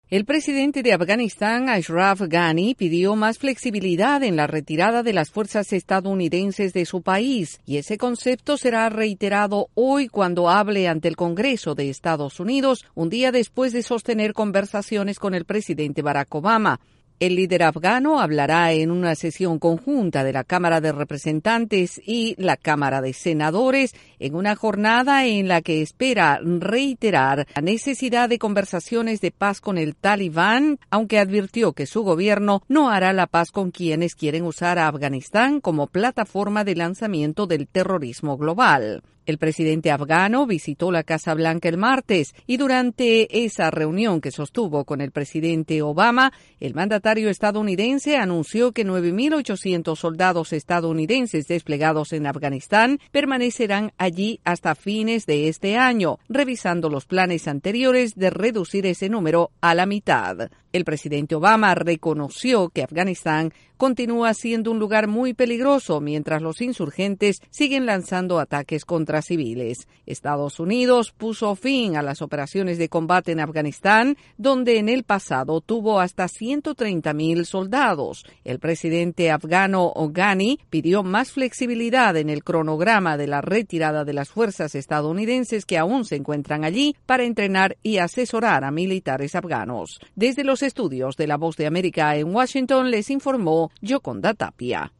El presidente de Afganistán agradeció, en un discurso en el Congreso, el sacrificio de los soldados estadounidenses. El informe